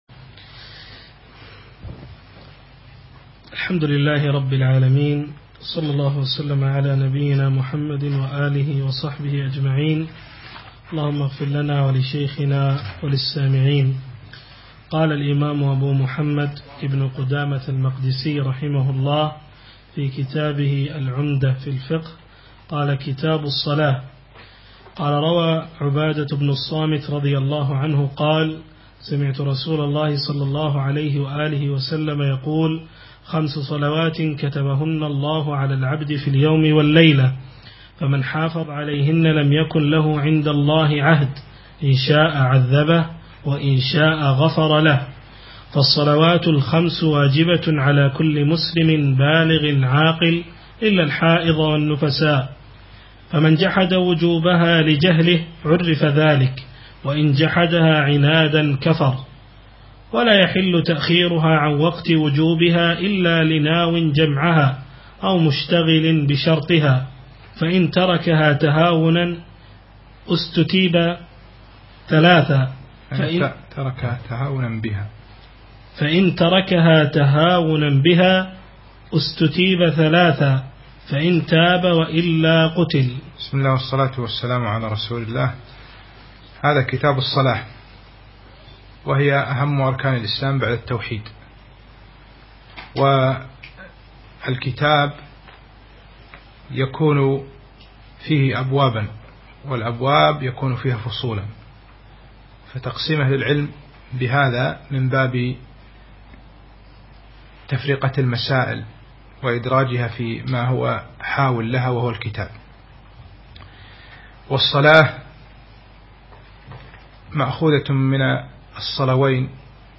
أقيمت الدورة في دولة قطر من يومي الجمعة والسبت 19 و 20 صفر 1436 الموافق 11 و 12 من شهر ديسمبر 2014
الدرس الرابع